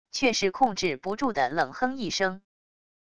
却是控制不住地冷哼一声wav音频生成系统WAV Audio Player